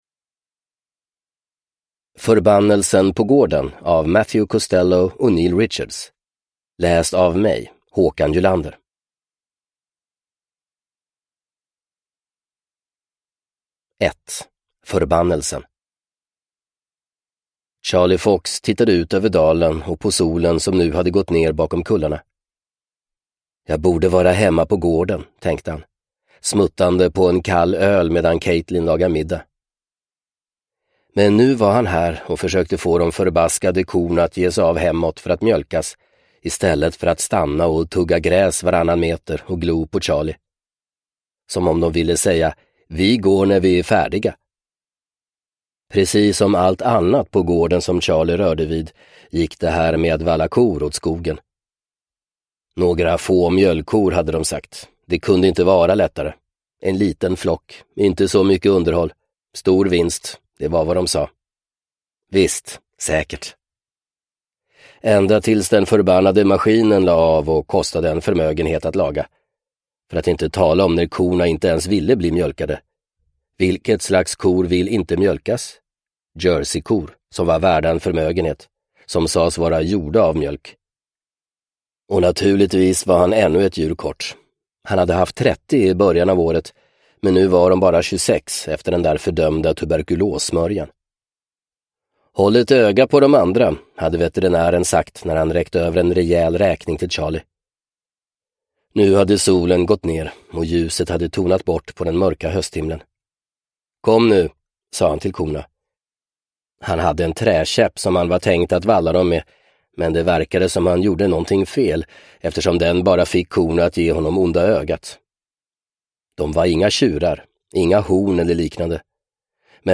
Förbannelsen på gården – Ljudbok
• Ljudbok